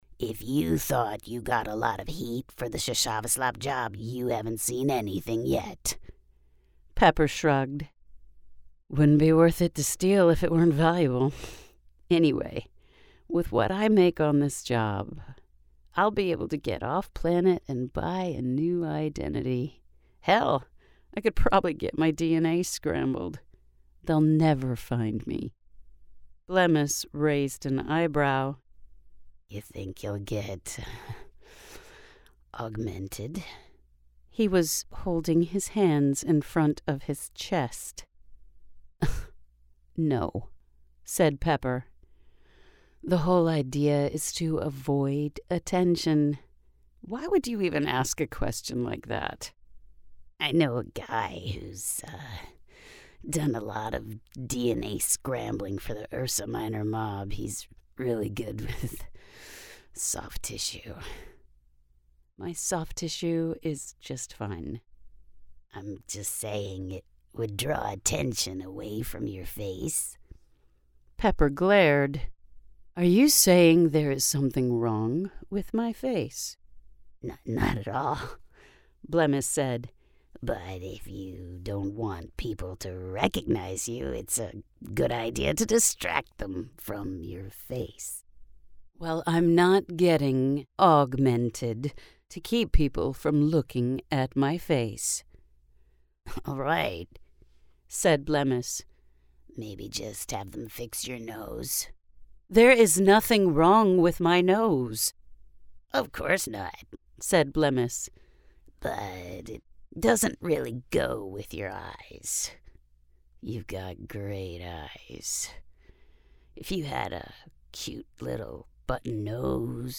In this sample, you hear three “voices”.
I let shades of Pepper color my narrator voice for this one, since it is her story, but there are slight differences in tone.
A little wrinkle to the nose and a dropped jaw help give Blemmis his distinctive sound.